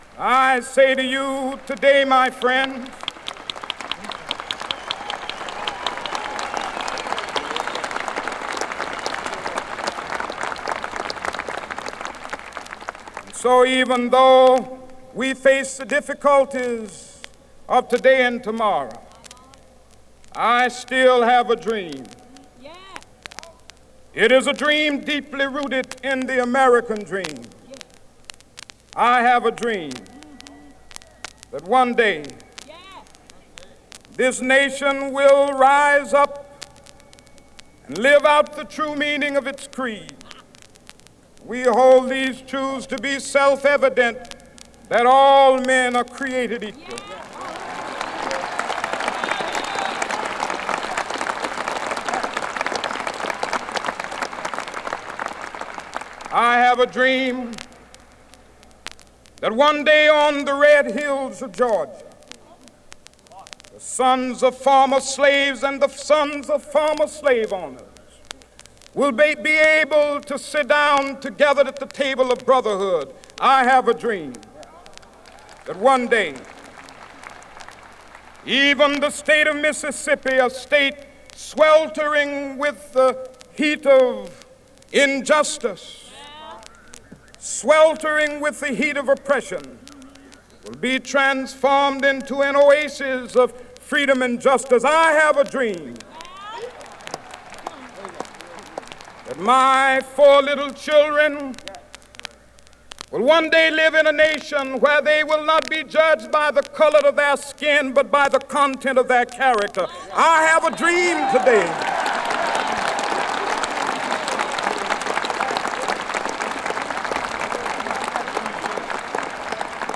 B面にはキング牧師による「I Have A Dream」をはじめとする4つの演説を収録。
チリパチ・ノイズ多。